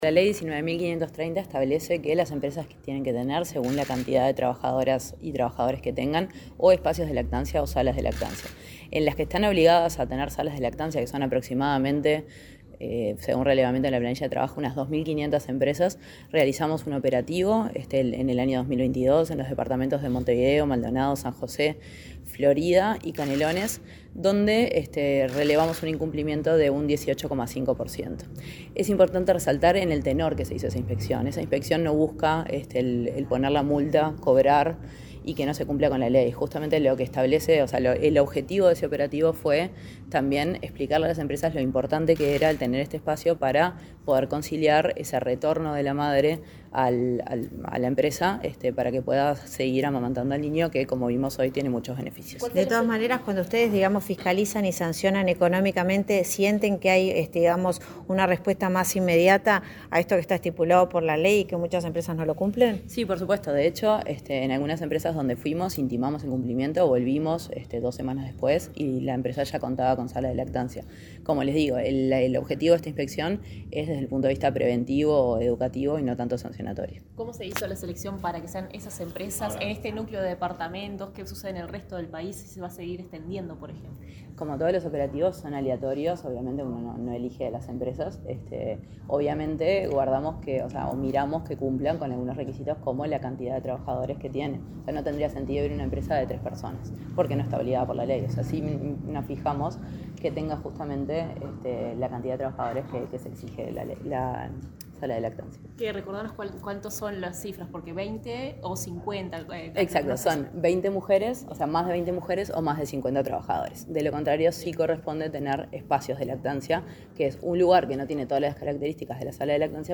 Declaraciones de la directora general del MTSS, Valentina Arregui
La directora general del Ministerio de Trabajo y Seguridad Social (MTSS), Valentina Arregui , dialogó con la prensa, luego de participar en un acto